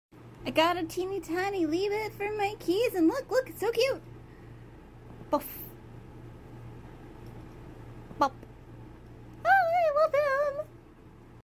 The magnetic bop sound makes me so happy. Sorry I onomatopoeia'd over it.